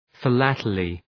Shkrimi fonetik {fı’lætəlı}